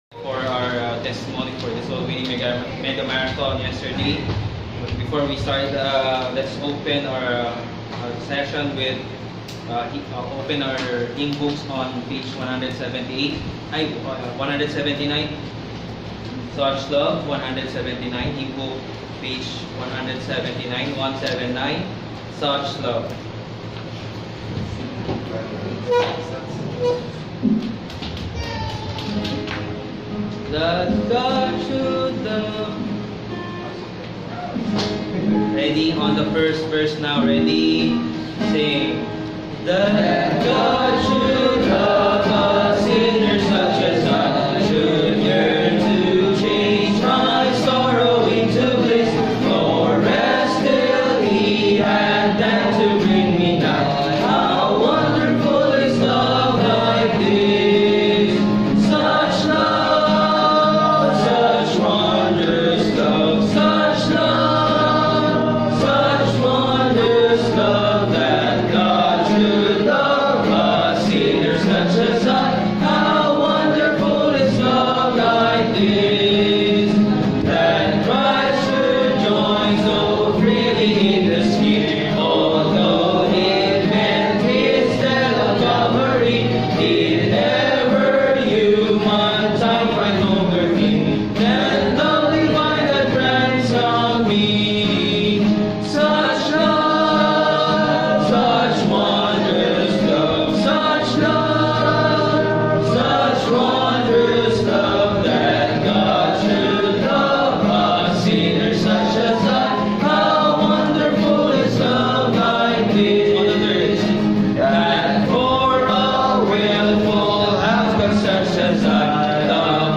2019 Testimonies VBC Manila
2019_Testimonies_VBC_Manila.mp3